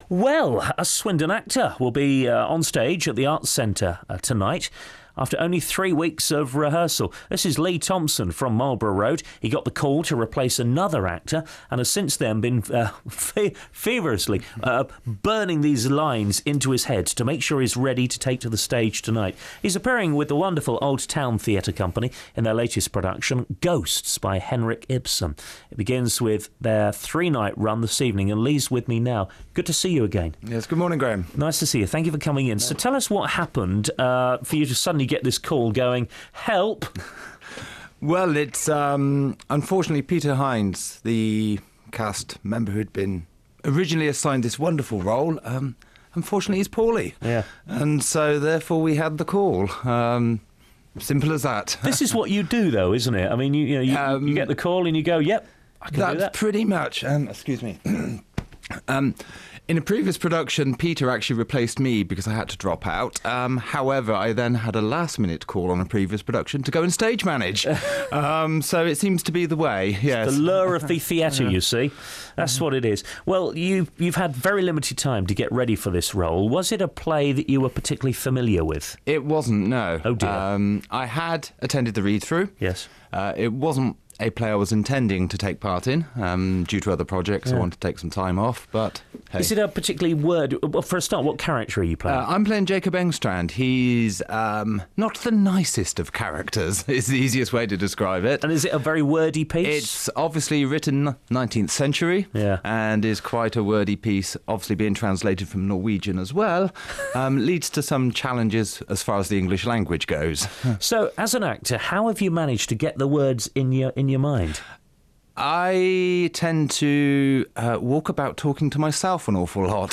BBC Radio Swindon Interview